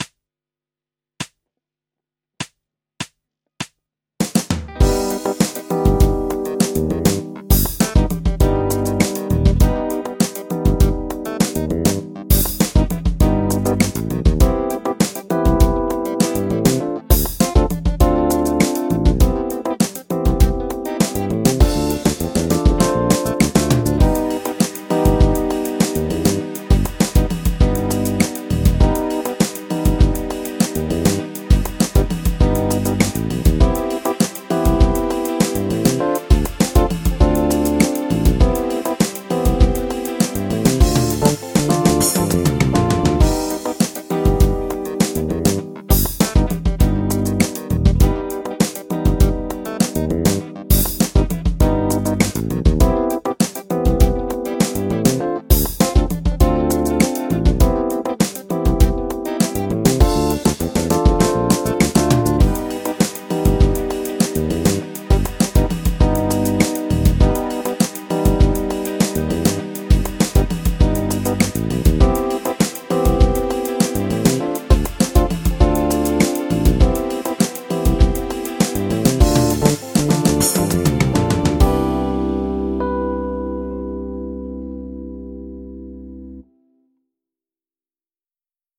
ホールトーン・スケール ギタースケールハンドブック -島村楽器